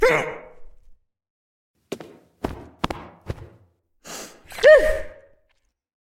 fakeout_death.mp3